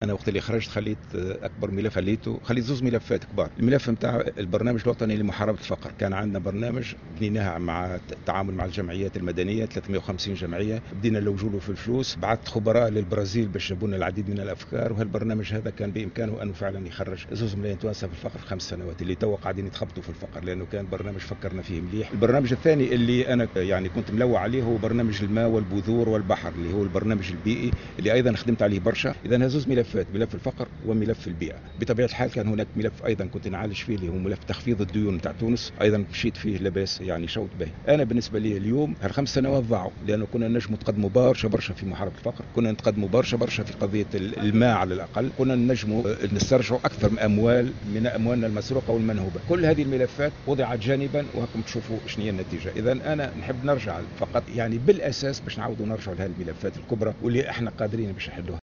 قال المترشّح للانتخابات الرئاسيّة منصف المرزوقي، اليوم السبت، إنه في حال فوزه في الانتخابات الرئاسية سيعيد فتح ملفات كبرى تم تركها جانبا عندما غادر قصر قرطاج. وأوضح في تصريح لمراسل "الجوهرة اف أم" على هامش حملته في المنستير، أن هذه الملفات تهتم بمحاربة الفقر والسعي الى اخراج مليوني تونسي من الفقر كما تتعلق هذه الملفات بالبيئة وأساسا قضايا الماء والبذور بالاضافة إلى تخفيض ديون تونس الخارجية.